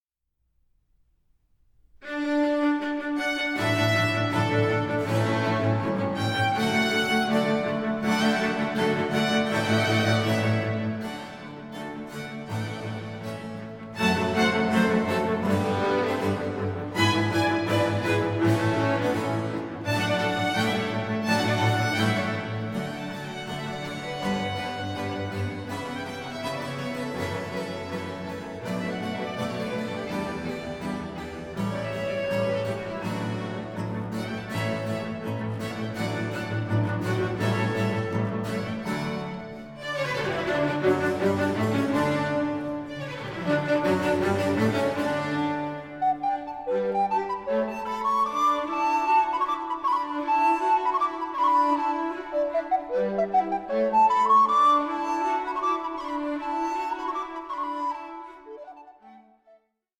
Recorder player
the recorder in place of the violin